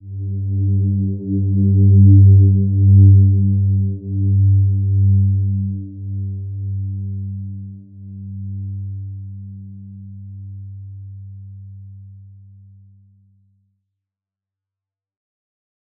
Silver-Gem-G2-p.wav